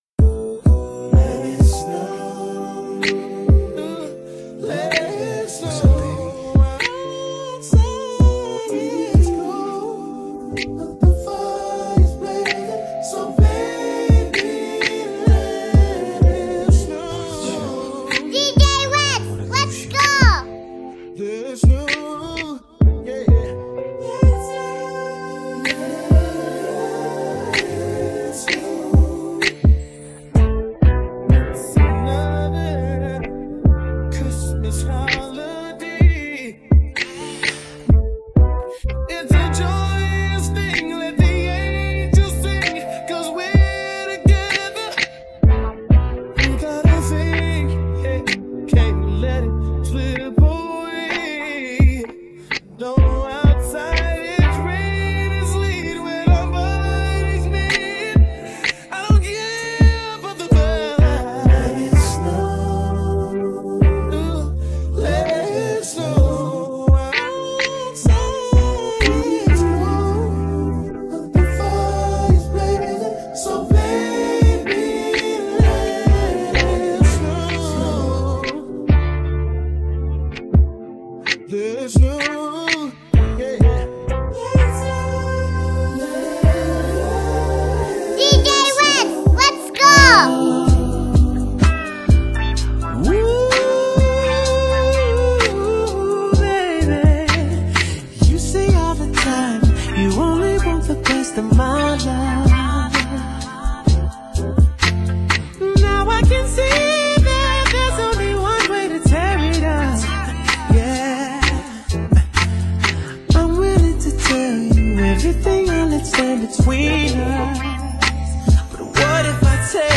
HipHop
RnB